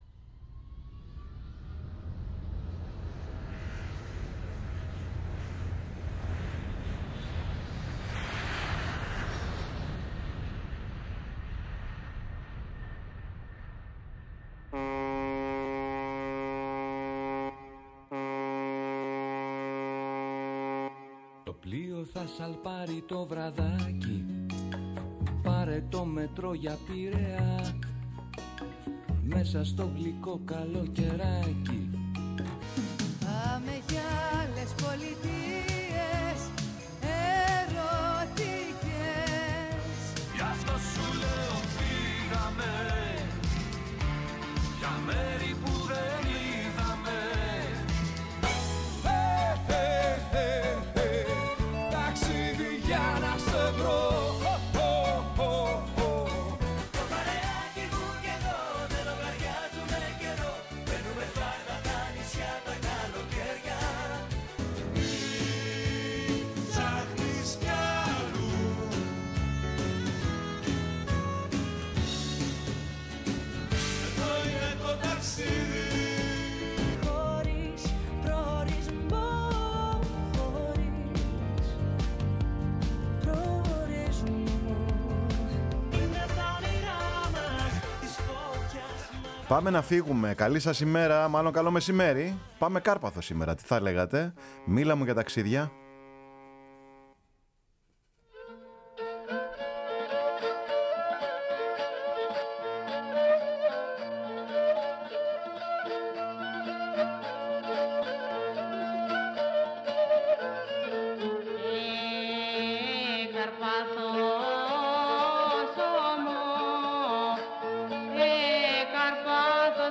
Ακούστε την εκπομπή, διανθισμένο με σκοπούς και τραγούδια της Καρπάθου και των Δωδεκανήσων.